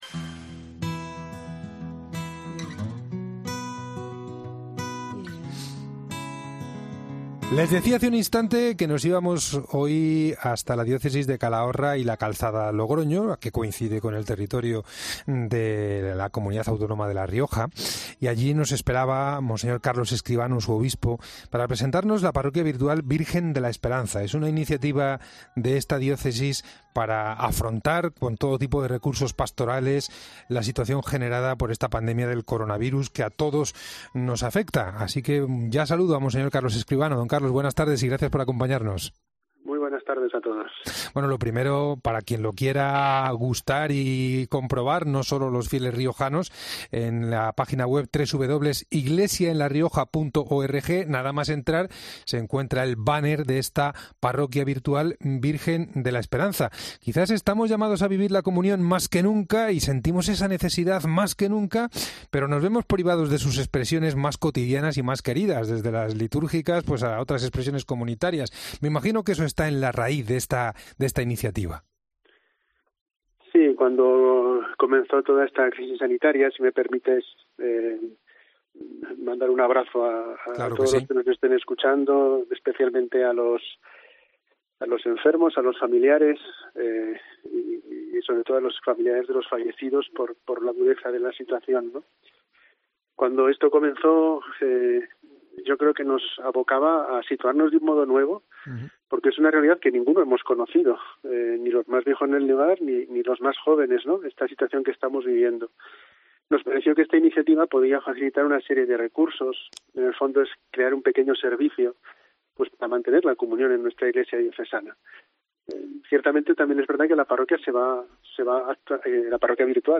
El obispo de la diócesis de Calahorra y la Calzada-Logroño presenta en El Espejo esta iniciativa para dar servicio a todos los fieles sin salir de...